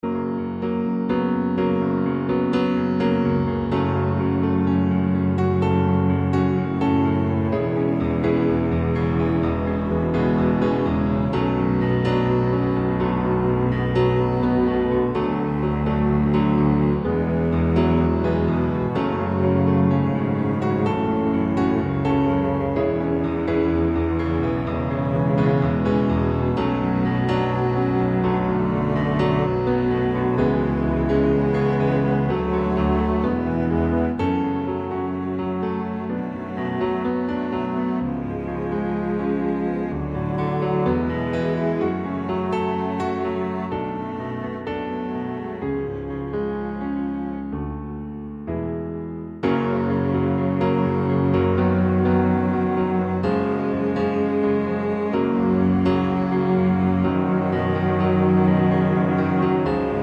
Down 4 Semitones